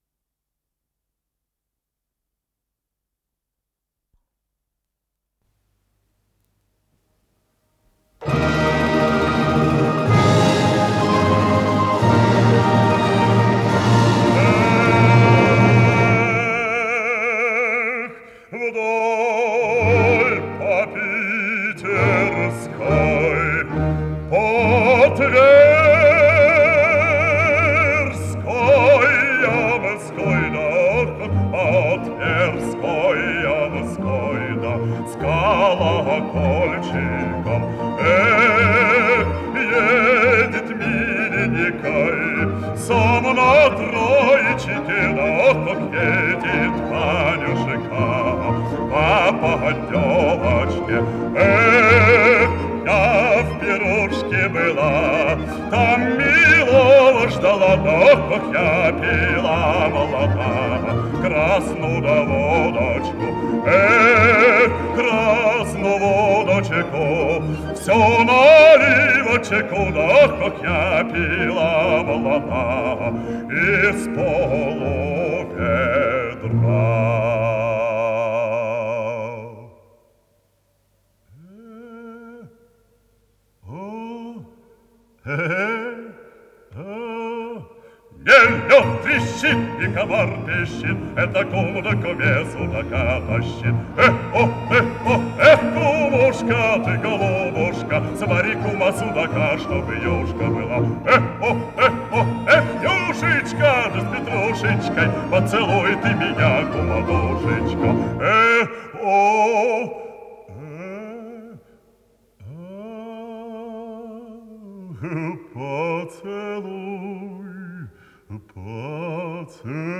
с профессиональной магнитной ленты
КомпозиторыРусская народная
ИсполнителиИван Петров - пение
АккомпаниментОркестр русских народных инструментов Всесоюзного радио и Центрального телевидения
Художественный руководитель и дирижёр - Владимир Федосеев
ВариантДубль моно